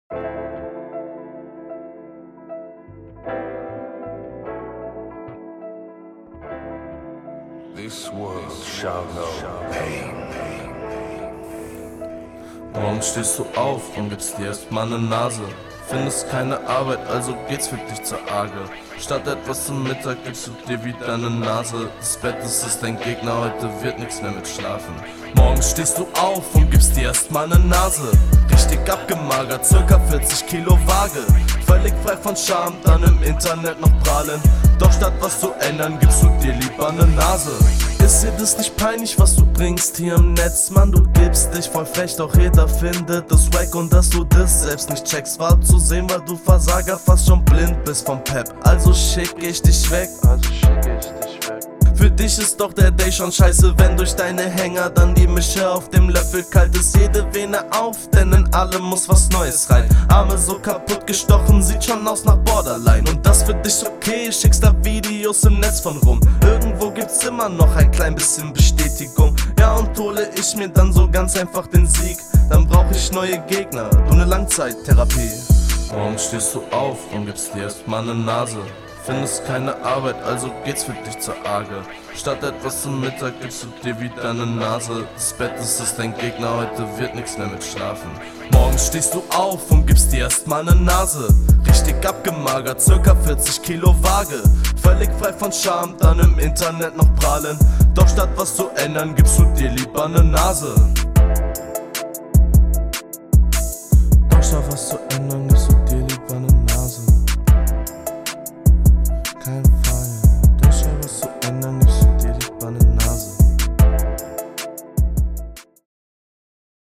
Beat auf entspannt.
Beatwahl finde ich schon geiler, doch vielleicht etwas zu ruhig für ein Battle.
Schönes Instrumental. Stimmlich sehr passend und flowst sehr smooth.